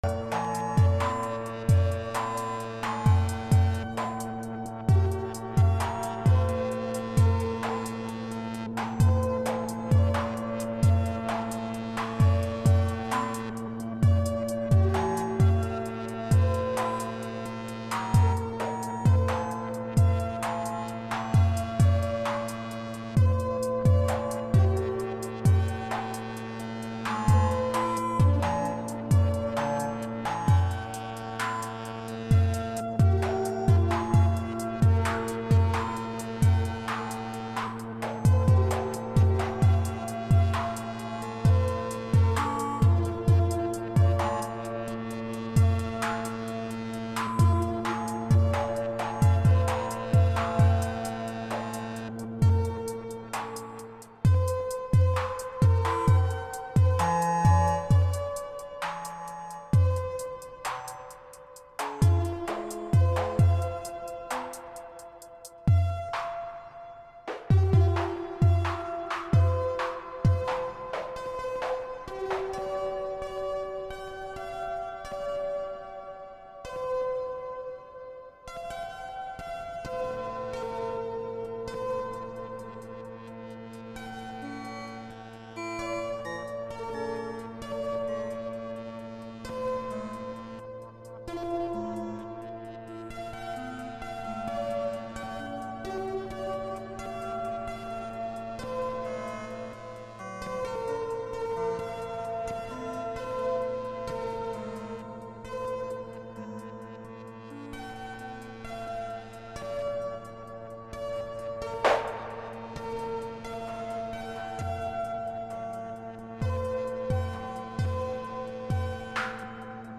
I set up a rather complex patch, clocked by the Marbles clone and modulated largely by the Abacus (Maths clone).